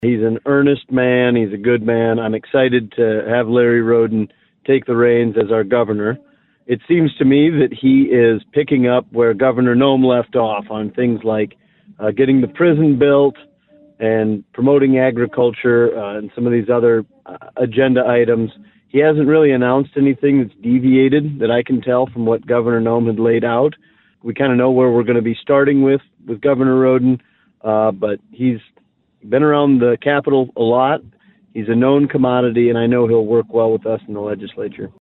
Former House Majority Leader Representative Will Mortenson of Fort Pierre calls Rhoden a “true, blue South Dakotan.”